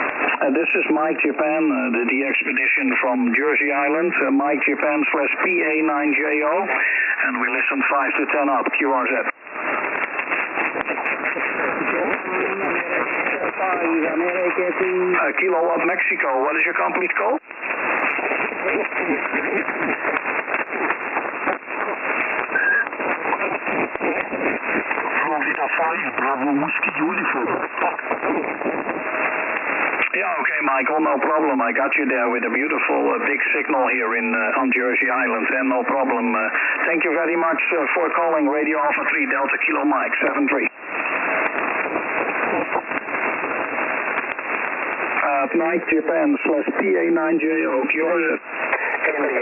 on 40m SSB